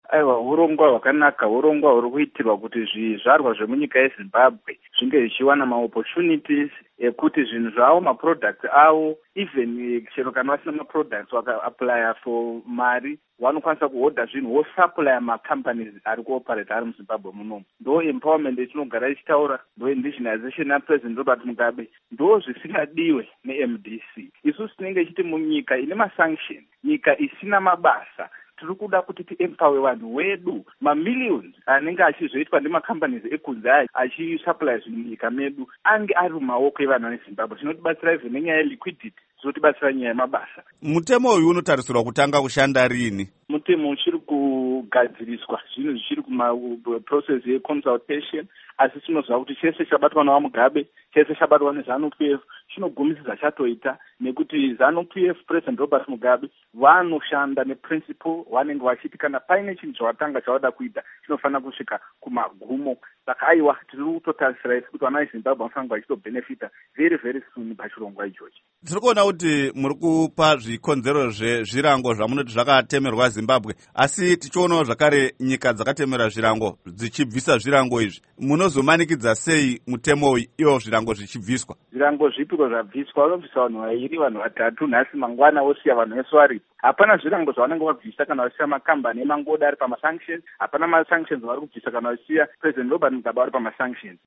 Embed share Hukuro naVaPsychology Maziwisa by VOA Embed share The code has been copied to your clipboard.